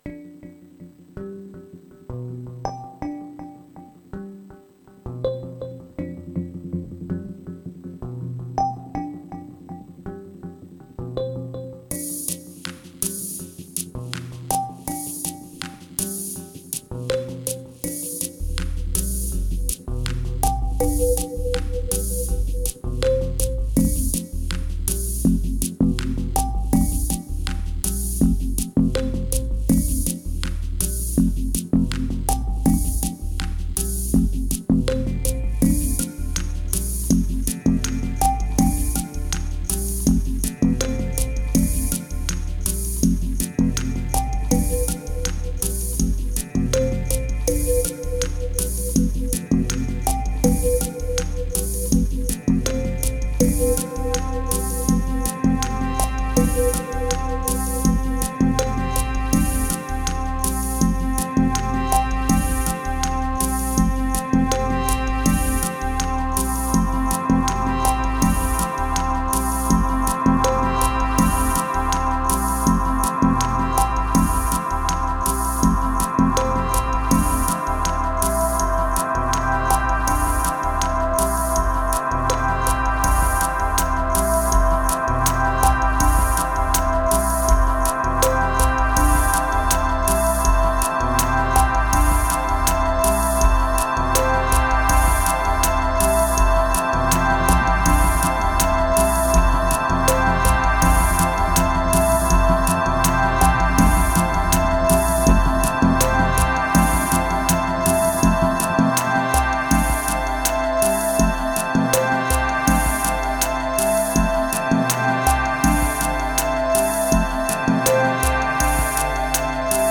(MPC X + Xone 92 + Eventide Space)
645📈 - 29%🤔 - 81BPM🔊 - 2021-04-27📅 - 194🌟
Moods Lights Relief Hope Deception Lame Solitary Deep Spring